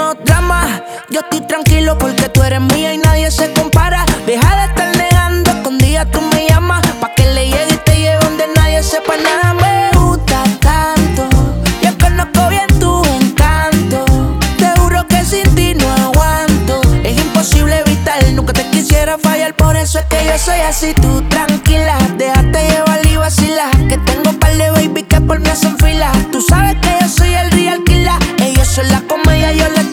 • Latin